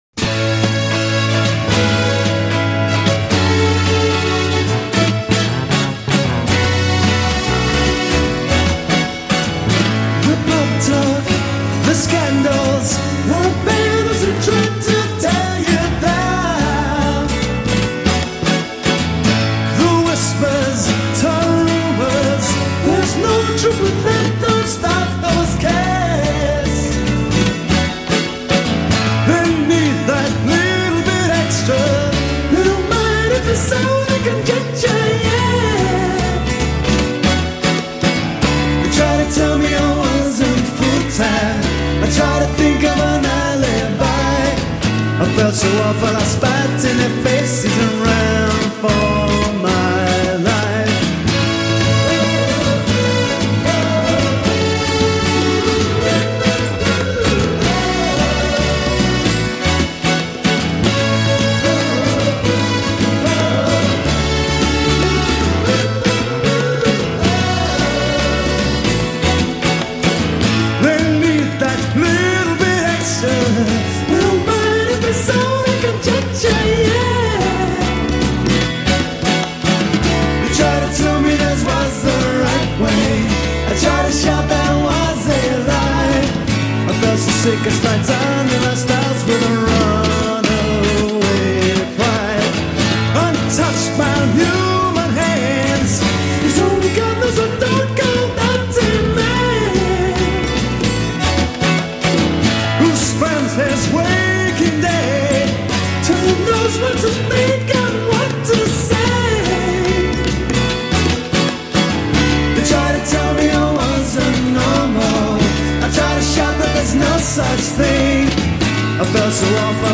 in session at The BBC